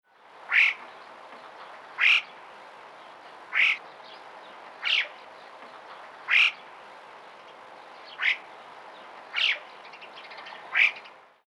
Genre: Animal Sound Recording.
NAJUversum_Gebirge_Alpendohle_Sound_Tierstimmenarchiv_Museum_fuer_Naturkunde_Berlin_short.mp3